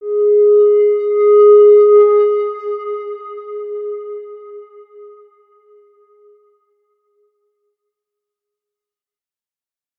X_Windwistle-G#3-ff.wav